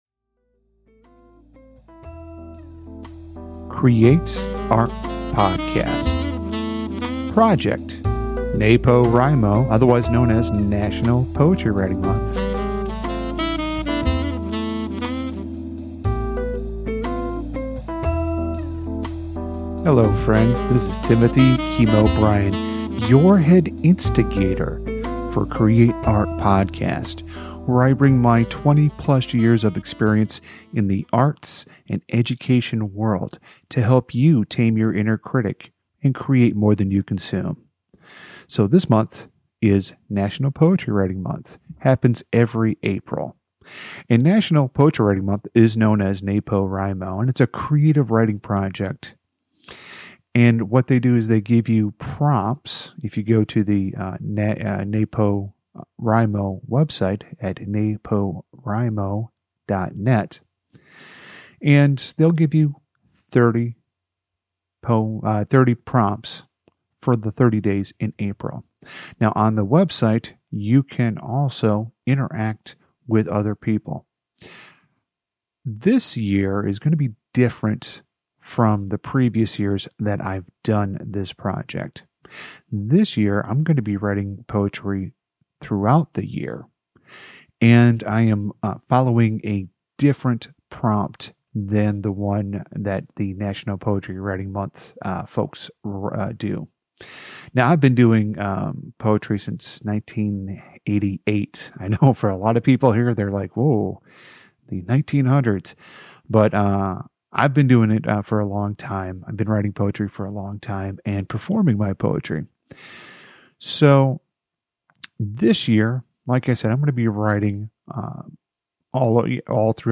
Some of the podcast episodes you will hear will be a live recording of me reading the poem to a live audience, other times I will be reading it in the comfort of my home studio.